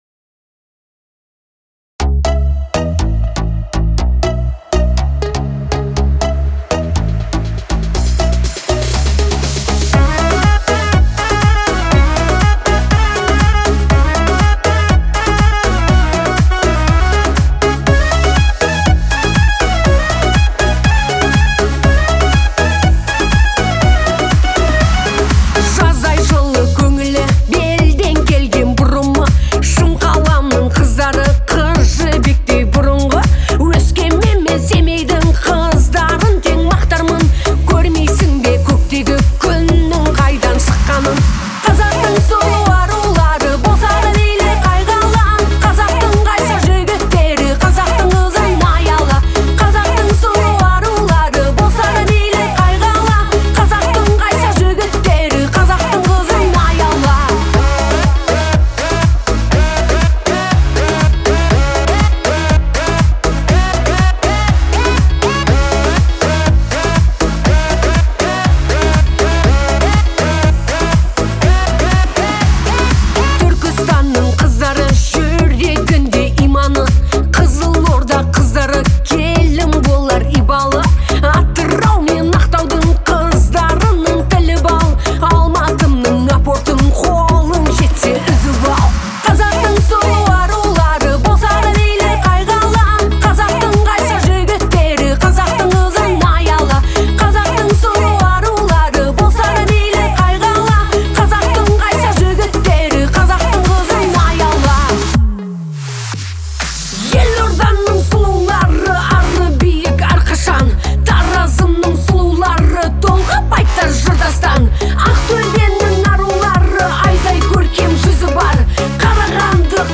это яркая и мелодичная песня в жанре народной музыки
а традиционные инструменты добавляют аутентичности звучанию.